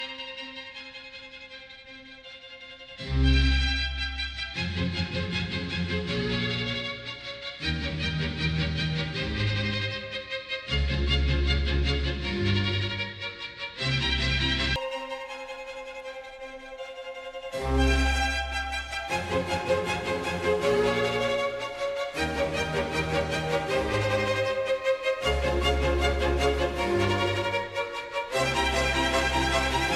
Our ANC technology is broadband low-processing and can work without porting advanced signal processing algorithms.